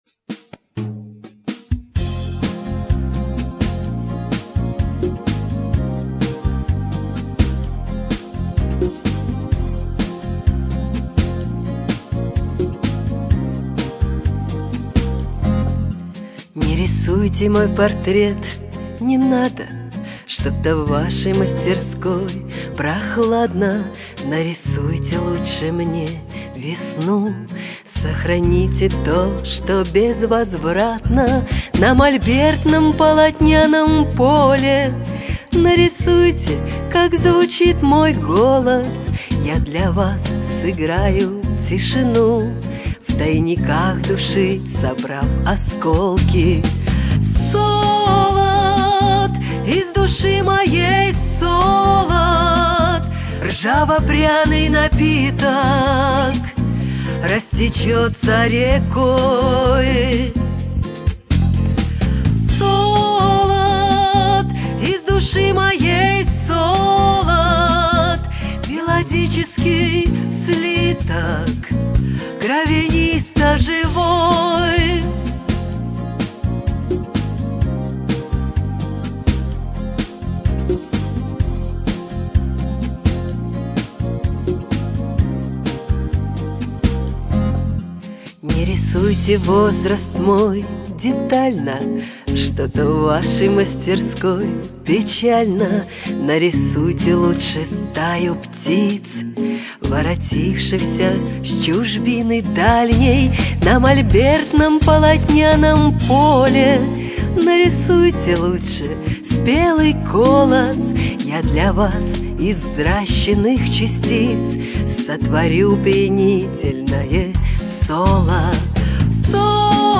гитары